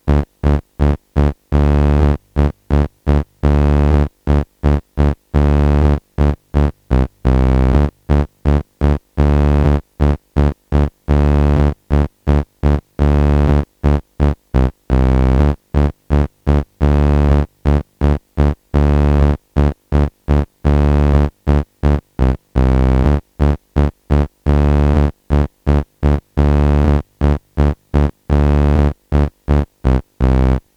bassottelua3.ogg